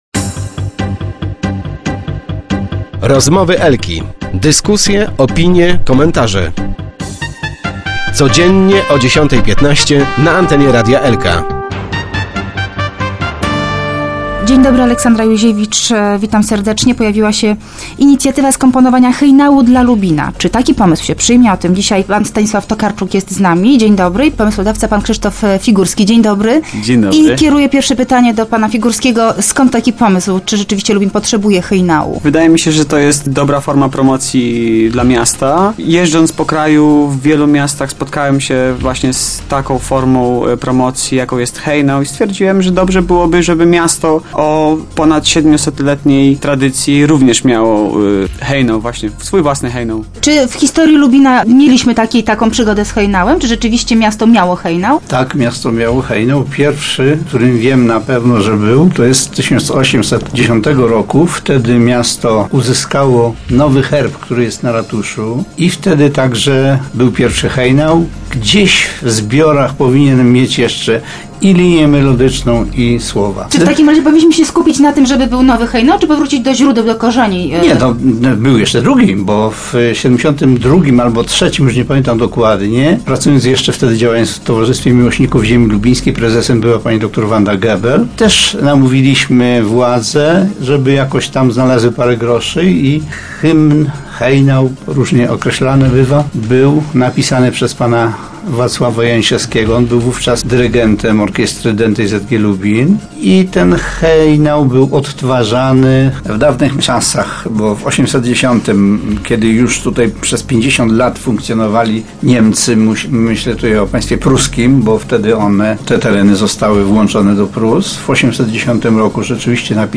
w lubińskim studio